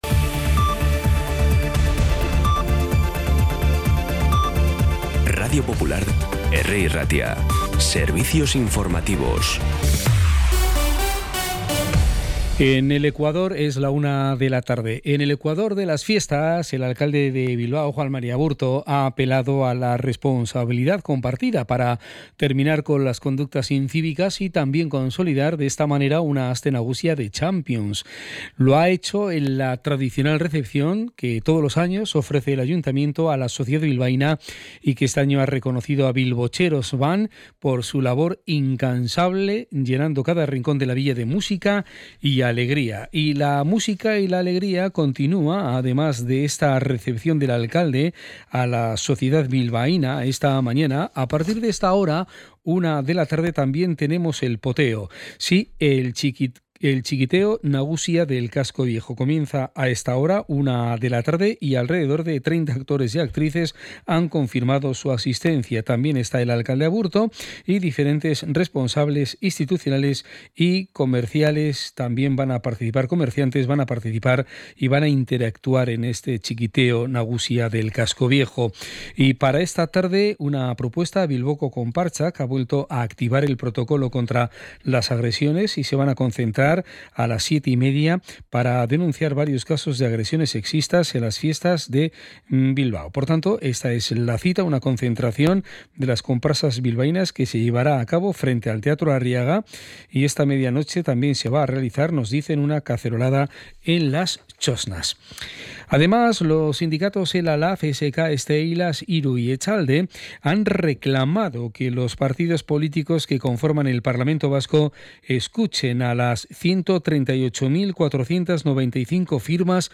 Las noticias de Bilbao y Bizkaia del 21 de agosto a las 13
Los titulares actualizados con las voces del día.